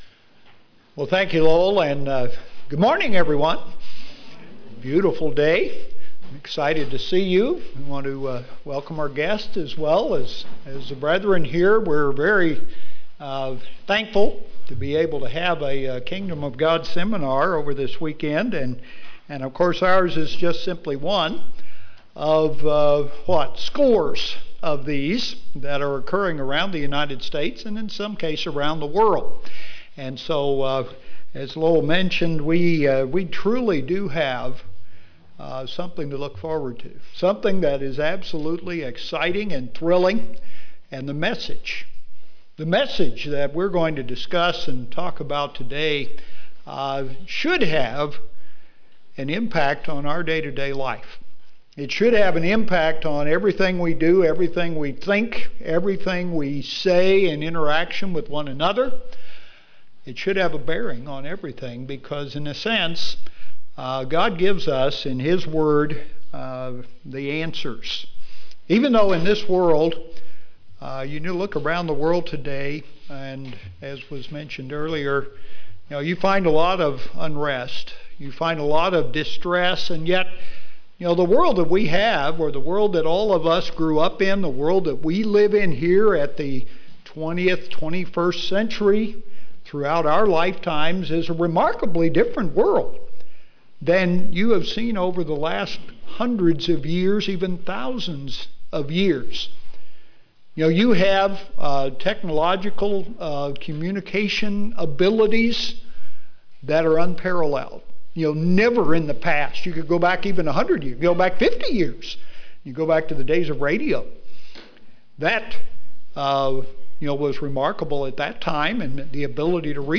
What do these days mean, and what is to come? The Kingdom of God is a message that has an impact on everything that we do and think. This is the May 12, 2012 Kingdom of God seminar in Kansas City.